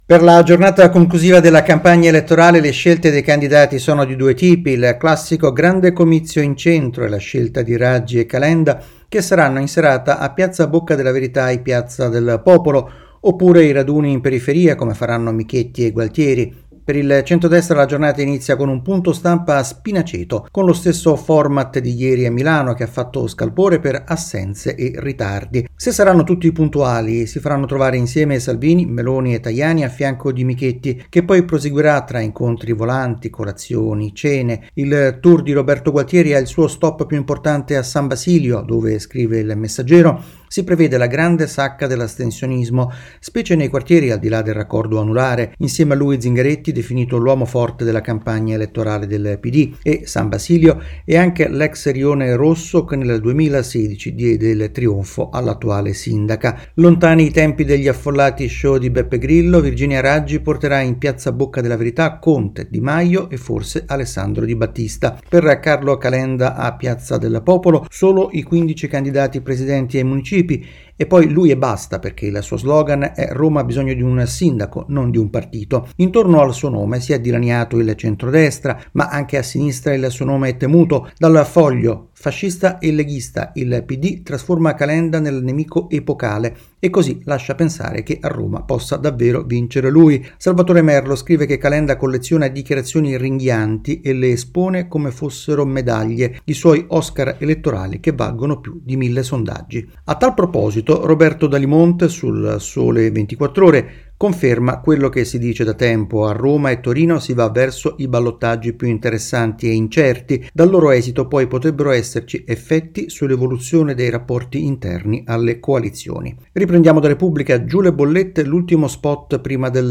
rassegna stampa